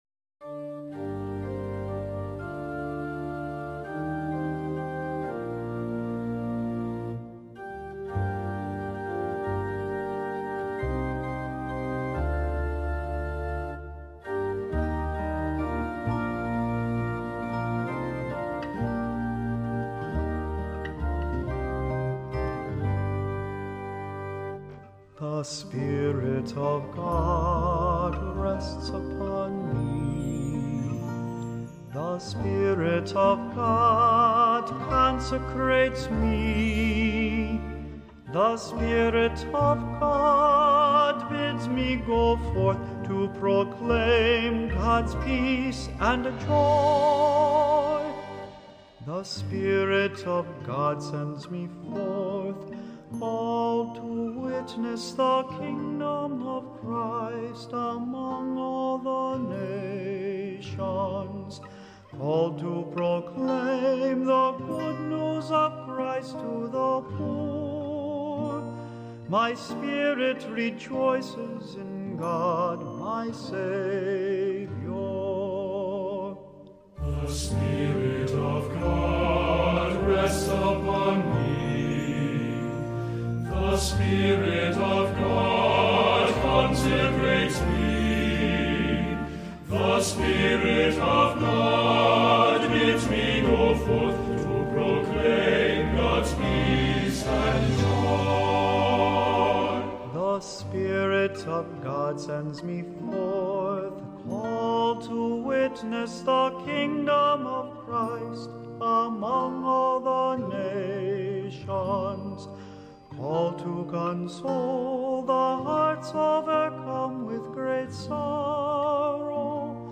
Voicing: "SATB, Cantor, Assembly"